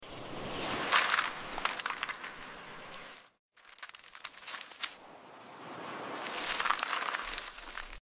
Satisfying ASMR videos of Dollar's